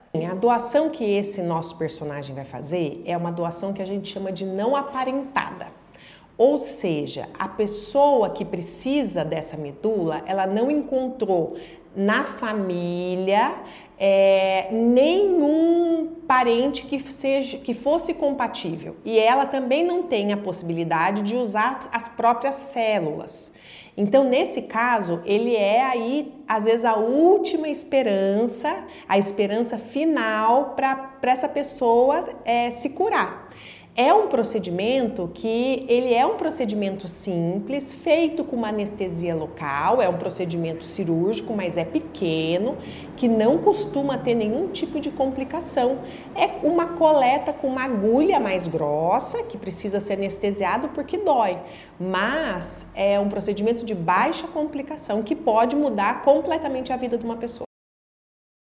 Sonora da responsável pelo Sistema Estadual de Transplante, Luana Tannous, sobre a doação de medula a paciente do Rio Grande do Norte